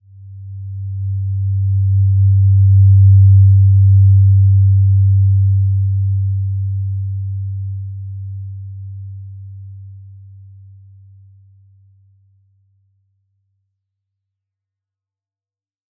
Simple-Glow-G2-mf.wav